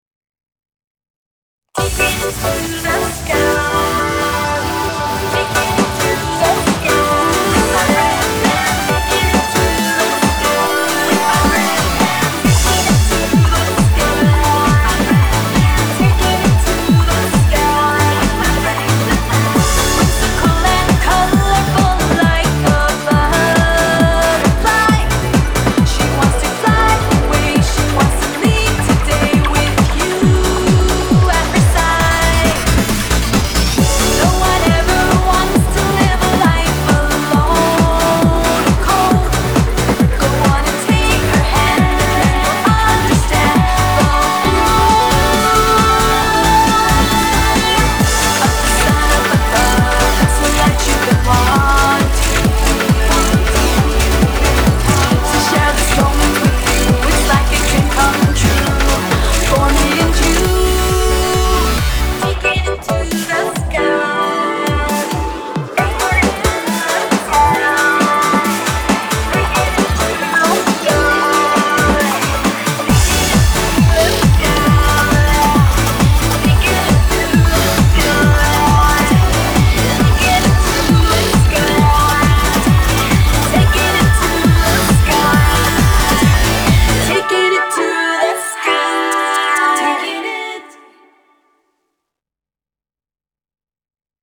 BPM135